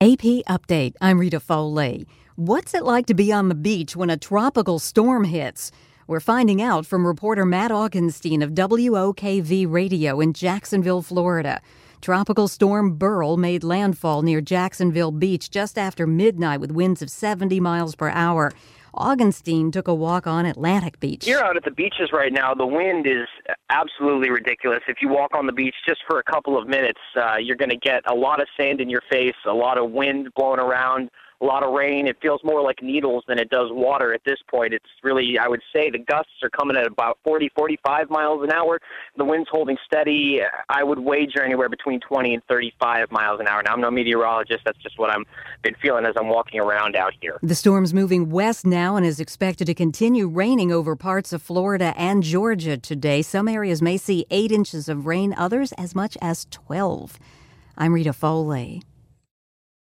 reports on Tropical Storm Beryl for AP Radio.